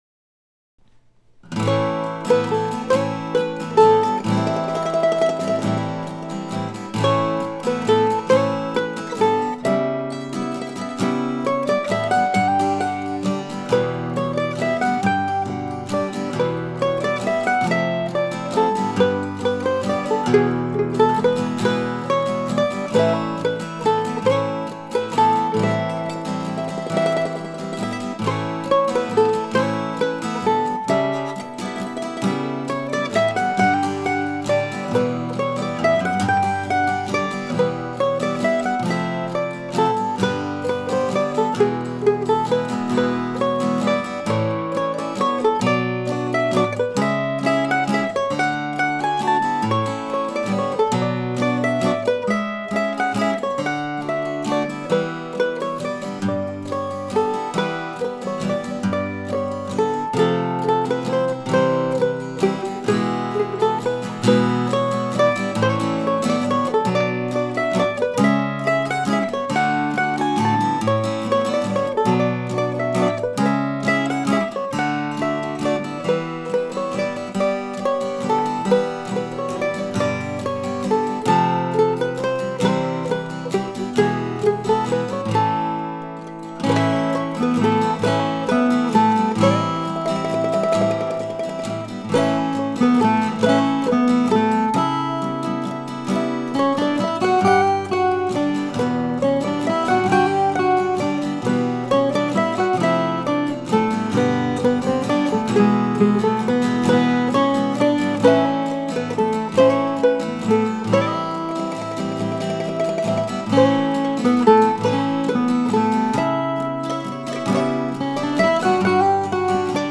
I like the major to minor bit at the start and in general how the tune wanders around. I realize that a certain percentage of excellent musicians will be put off by the half-diminished (minor 7, flat 5) chord in the A section.
I also know this is two waltzes in a row.